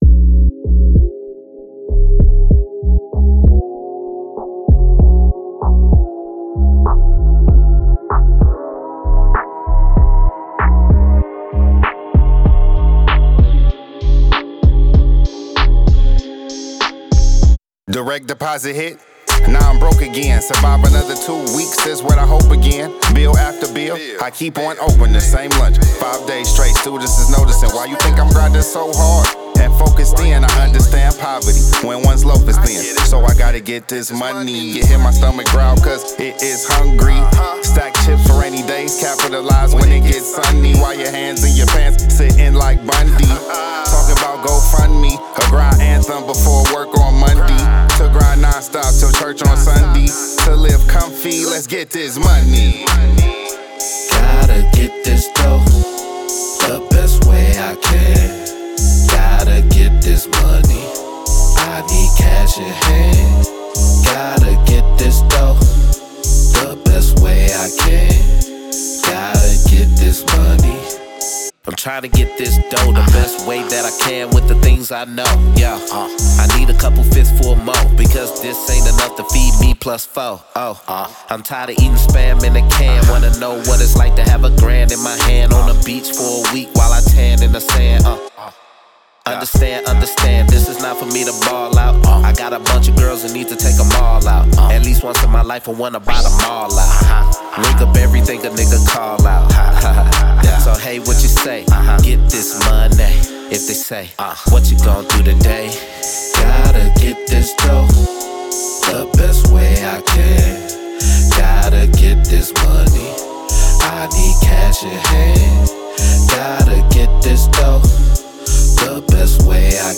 Old School Hip-Hop